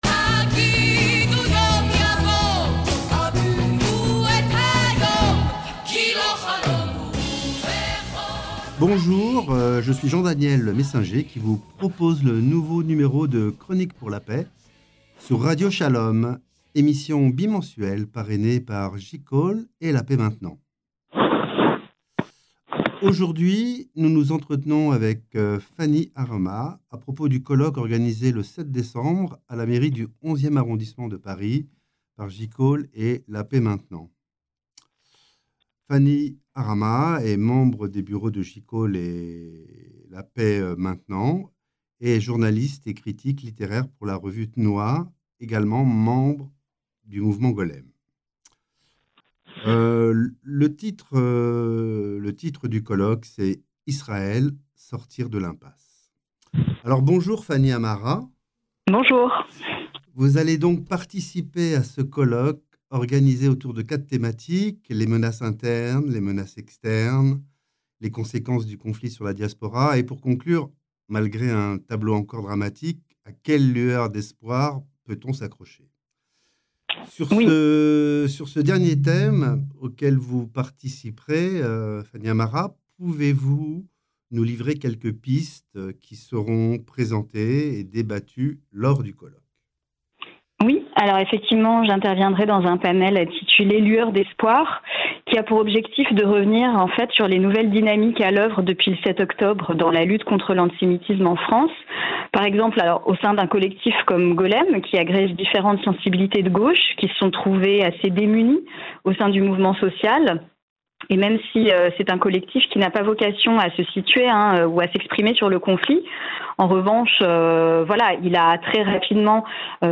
émission bimensuelle sur Radio Shalom